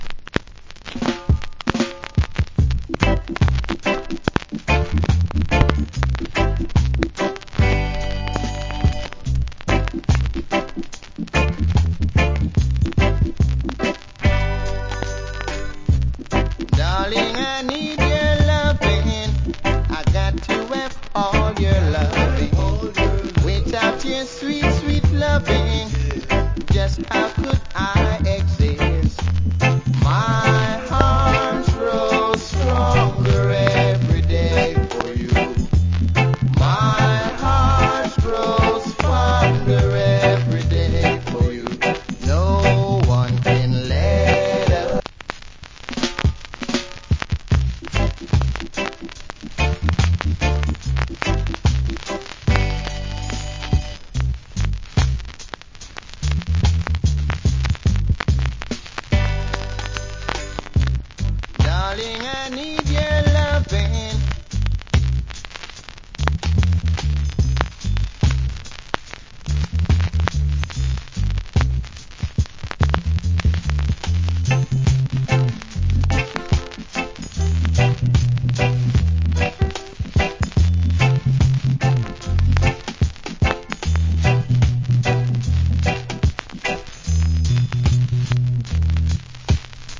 Reggae Vocal.